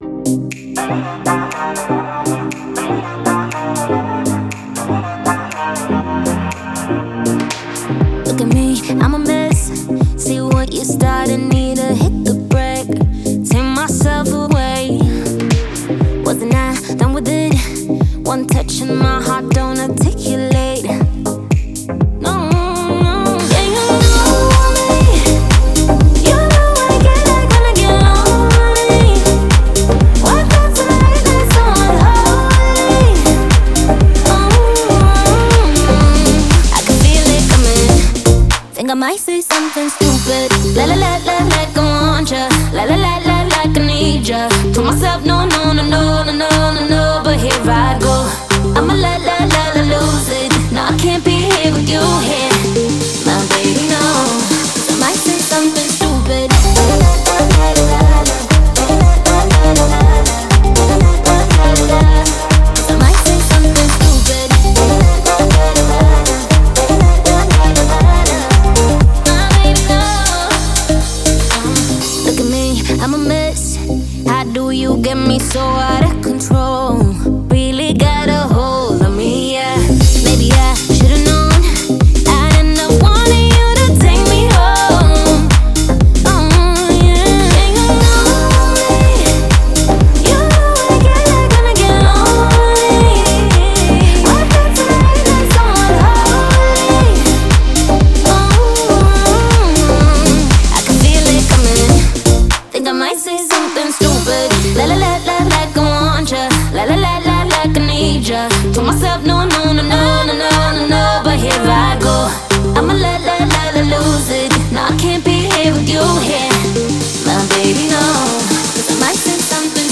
ритмичным битом, создающим атмосферу веселья и легкости